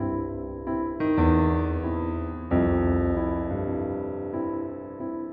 描述：爵士乐 HipHop 钢琴
Tag: 90 bpm Jazz Loops Piano Loops 919.46 KB wav Key : G